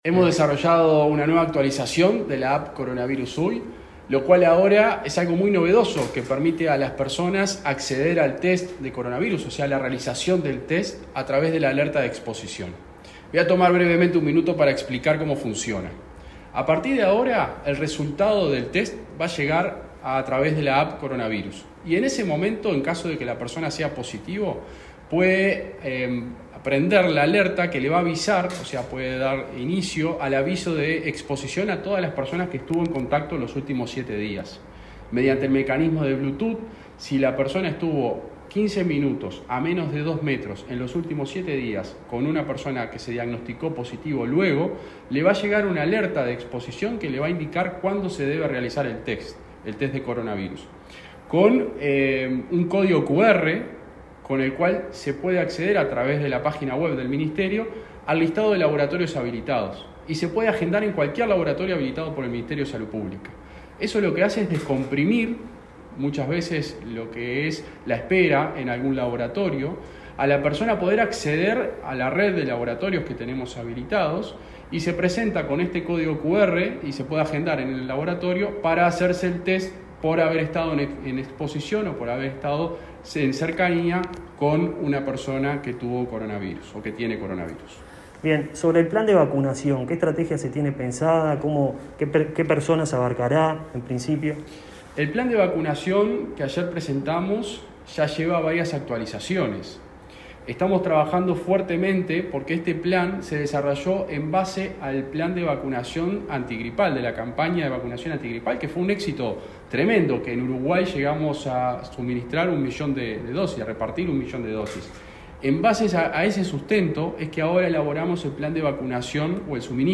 Declaraciones del subsecretario de Salud, José Luis Satdjian, sobre actualización de aplicación Coronavirus UY y plan de vacunación